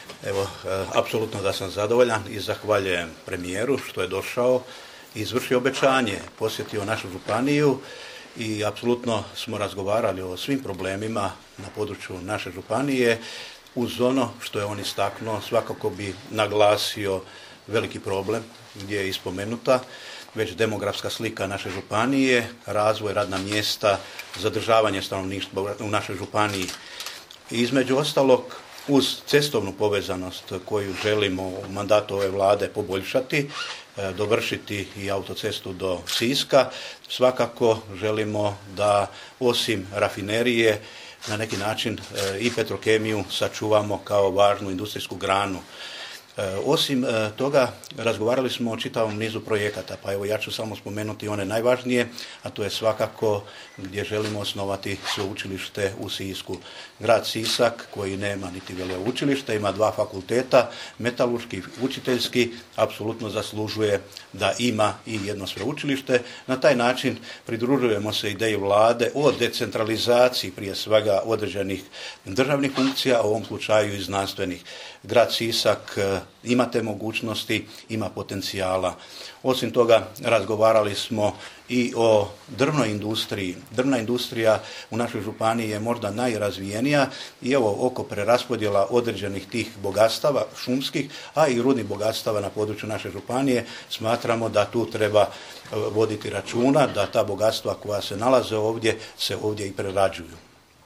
Sisačko-moslavački župan Ivo Žinić: